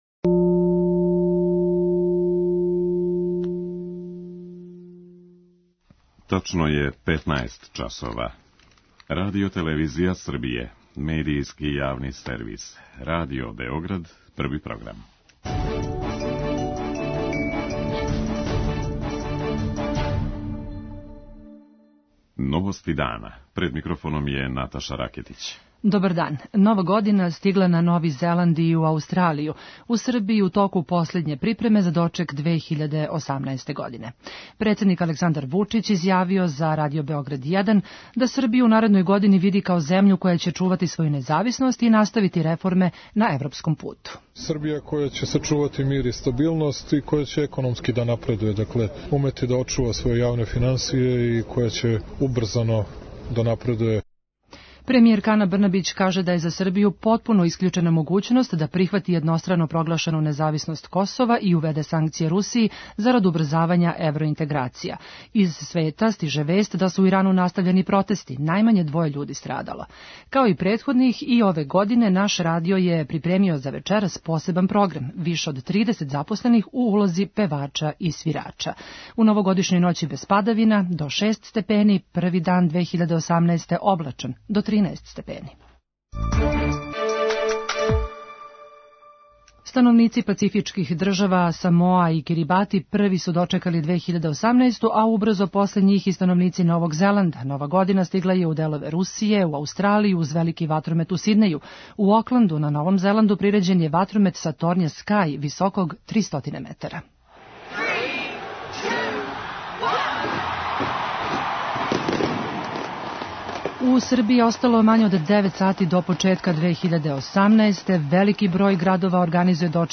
Председник Србије Александар Вучић изјавио је за Радио Београд 1 да Србију у 2018. види као земљу која ће чувати своју независност, наставити реформе на европском путу, и као земљу која ће сачувати мир и стабилност.